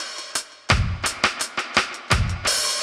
Index of /musicradar/dub-designer-samples/85bpm/Beats
DD_BeatB_85-01.wav